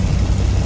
ap_airship_engine.ogg